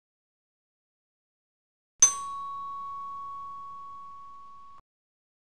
sound effect BELLS CHIME (320 kbps)
Category: Sound FX   Right: Personal